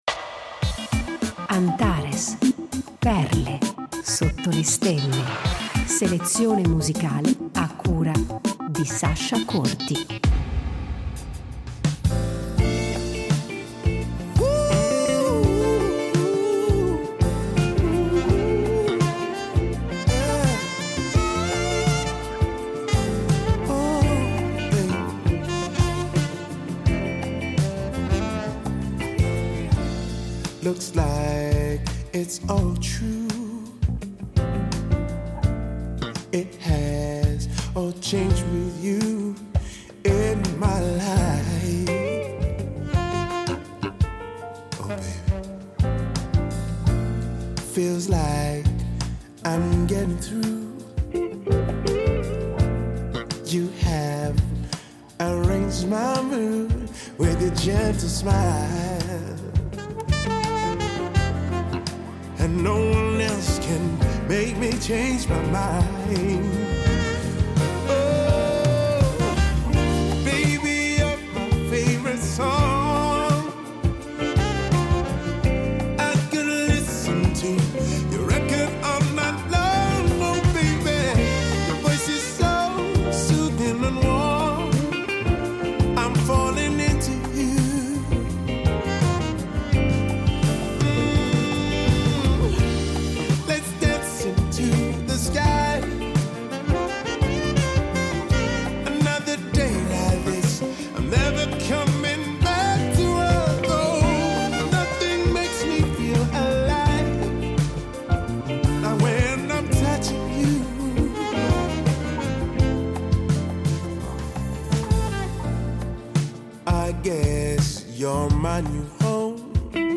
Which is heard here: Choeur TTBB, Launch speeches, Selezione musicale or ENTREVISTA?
Selezione musicale